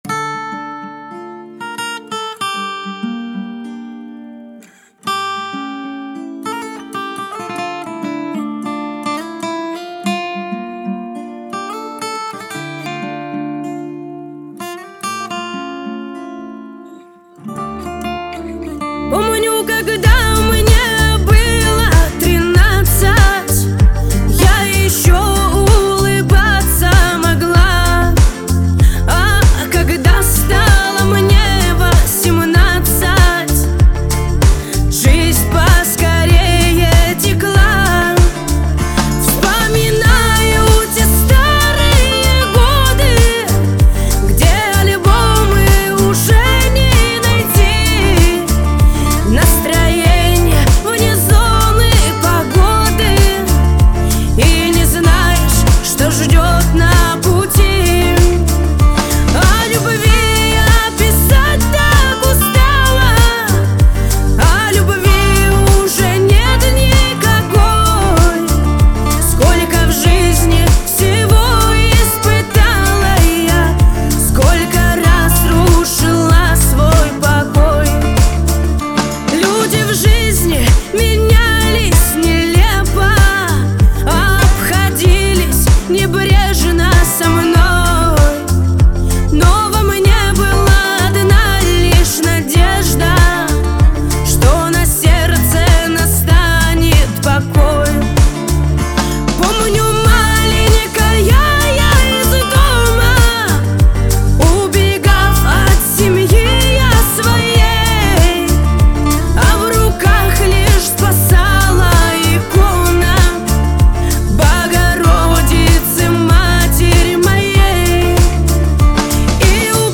Поп музыка, Русские поп песни